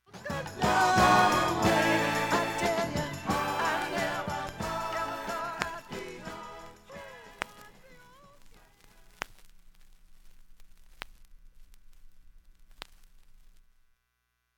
盤面きれいです音質良好全曲試聴済み
A-1終わりフェイドアウト部に
かすかなプツが５回出ます